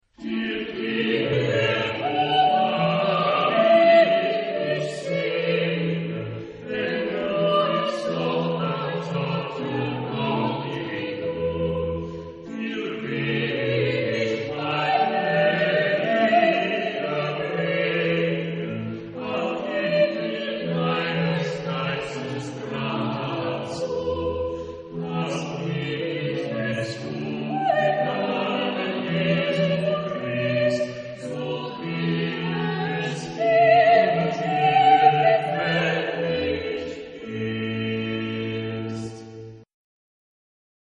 Genre-Style-Form: Sacred ; Hymn (sacred) ; Canticle
Instruments: Organ (1)
Tonality: G major